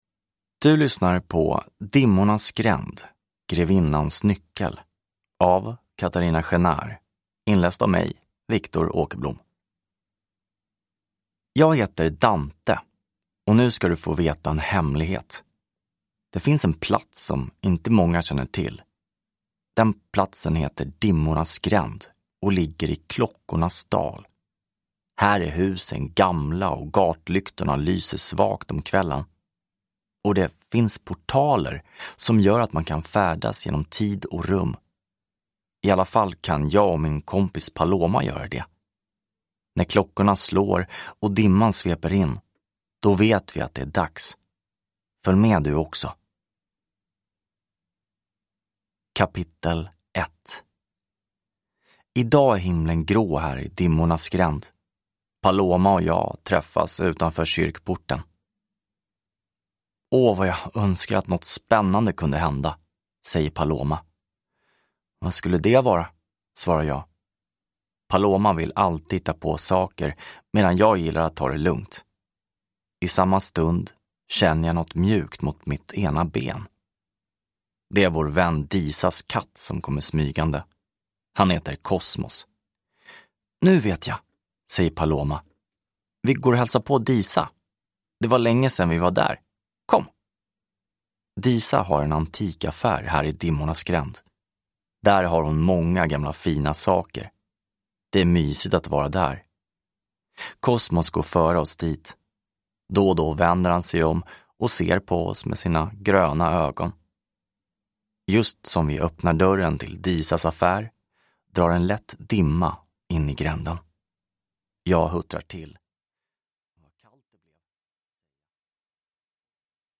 Grevinnans nyckel – Ljudbok